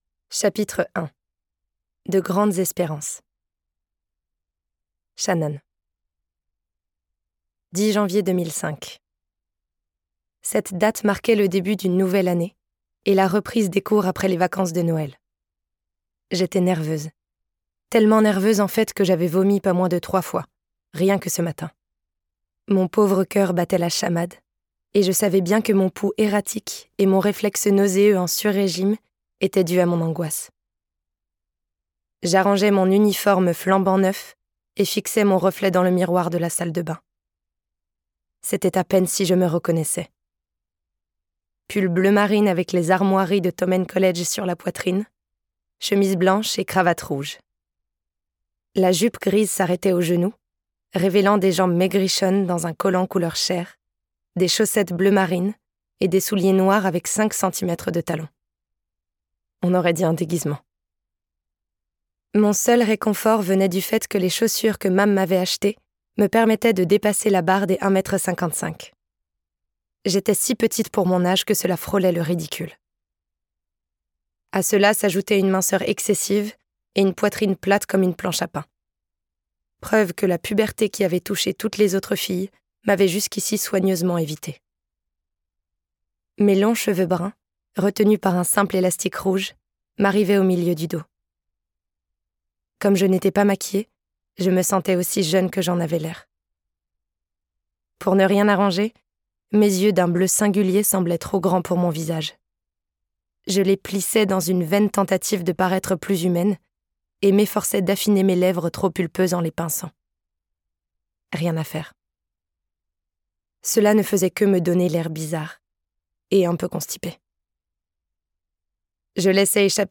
je découvre un extrait - Binding 13 - Boys of Tommen - Tome 1 de Chloe Walsh